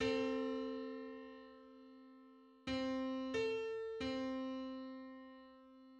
Just: 875/512 = 927.77 cents.
Public domain Public domain false false This media depicts a musical interval outside of a specific musical context.
Eight-hundred-seventy-fifth_harmonic_on_C.mid.mp3